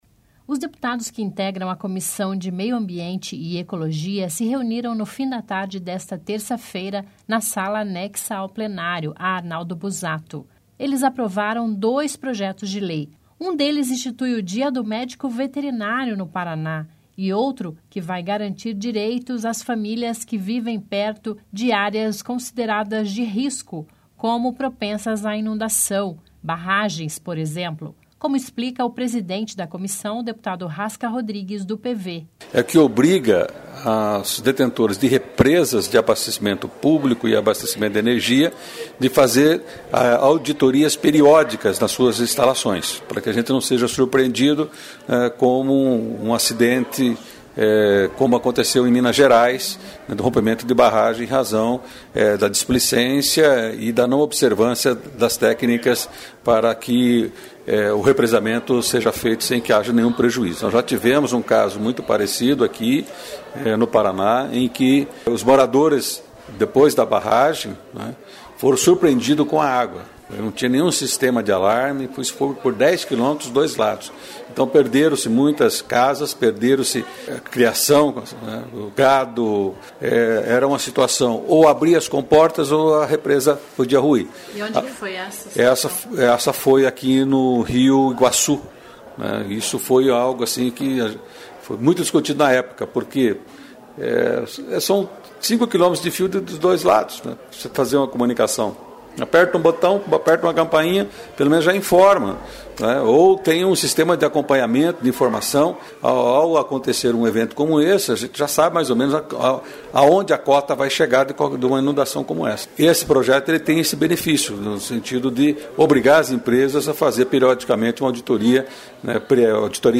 (Sonora/entrevista)